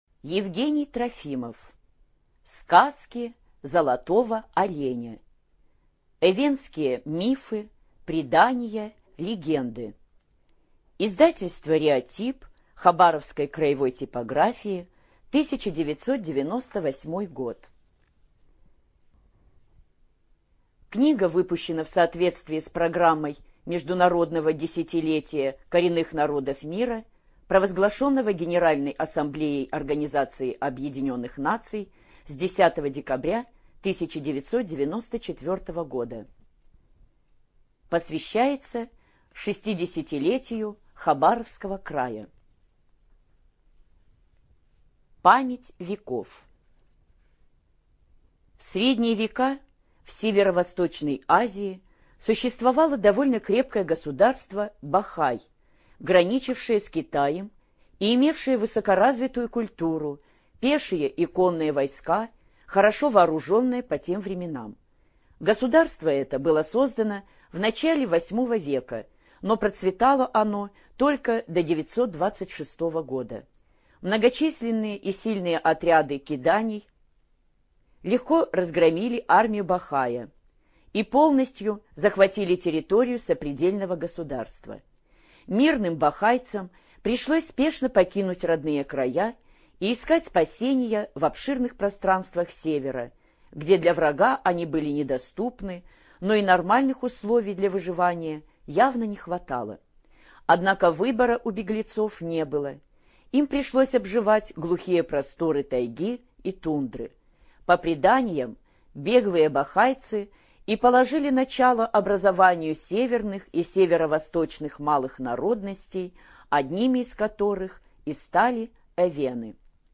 ЖанрДетская литература, Сказки
Студия звукозаписиХабаровская краевая библиотека для слепых